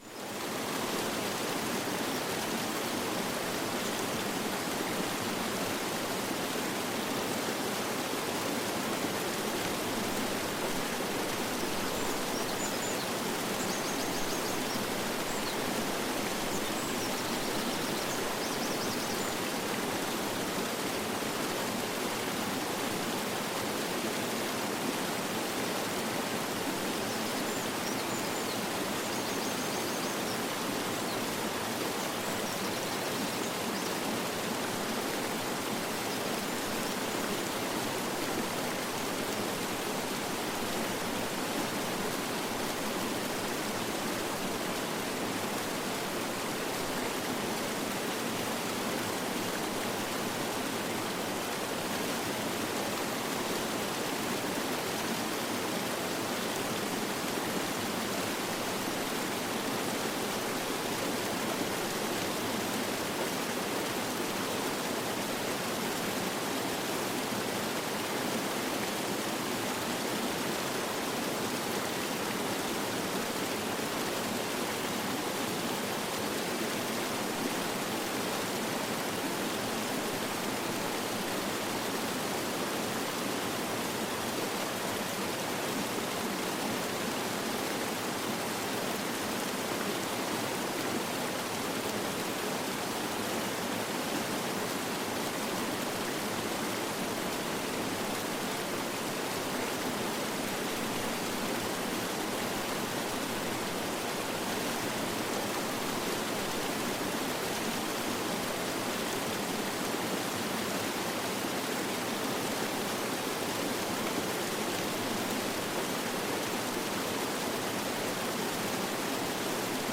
ERHOLSAMSTER SCHLAF: Naturklang-Schlaf mit Flussrauschen